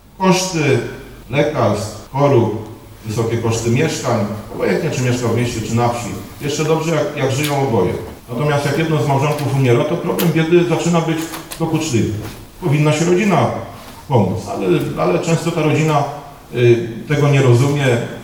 Podczas wczorajszego spotkania z rolnikami w Barzkowicach poseł Prawa i Sprawiedliwości, Jan Krzysztof Ardanowski chwalił flagowe programy swojej partii.